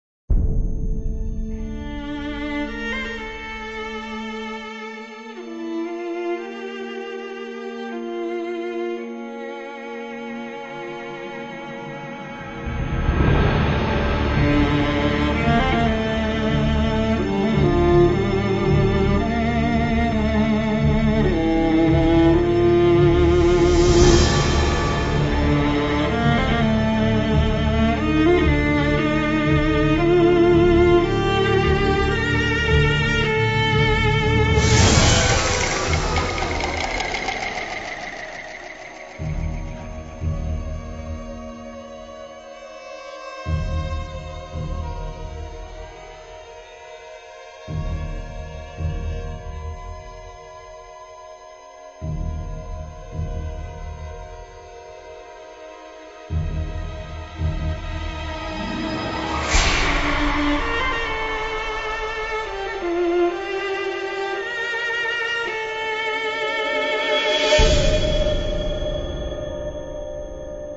Promotional CD tracks
violin